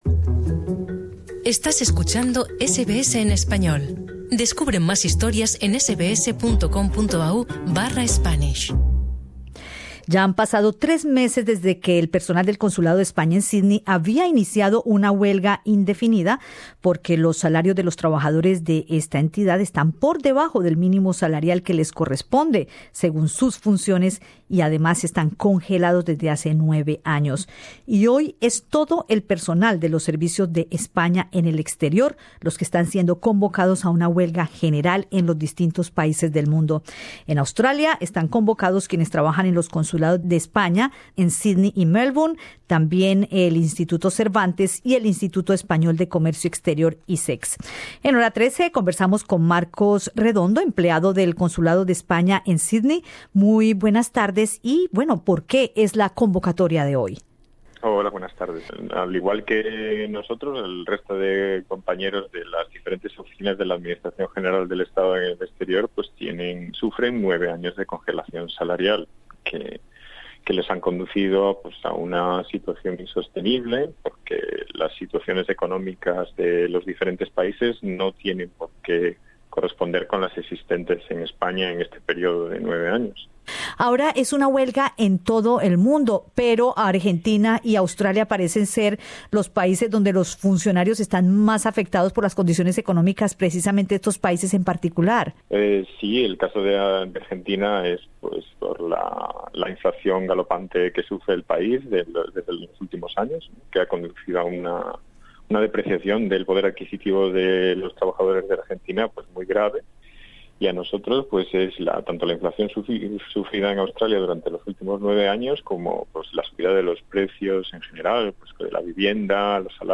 En Hora 13 conversamos